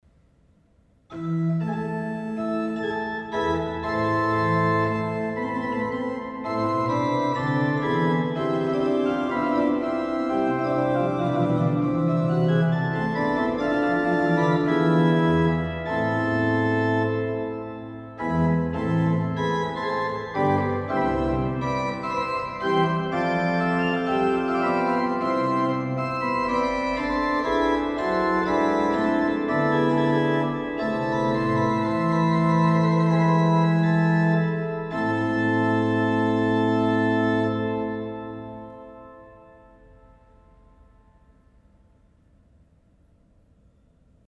Bourdon 8'
Flûte conique 4'
Tremblant doux.
Vous trouverez un très bel orgue-positif avec une construction artisanale en chêne massif avec 4 registres.
A' = 440 Hz./18°. Tempérament, inégale, Valotti